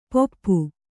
♪ poppu